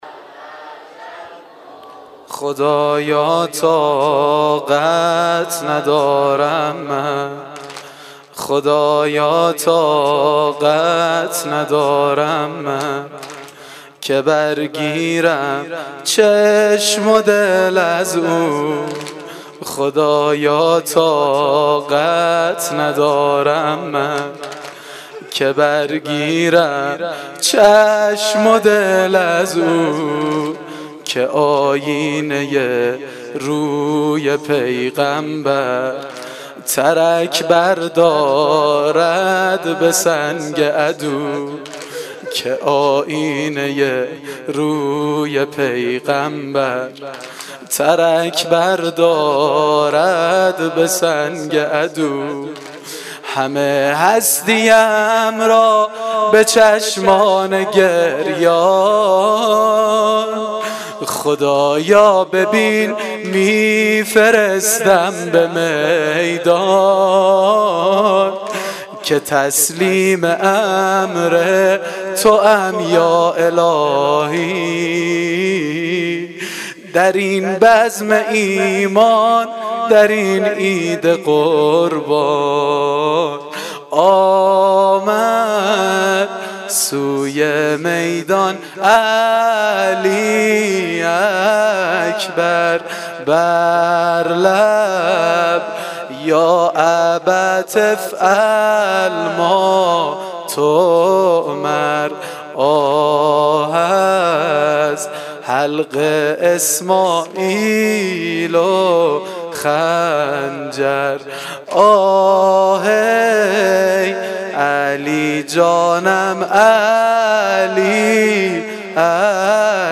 واحد سنگین شب هشتم محرم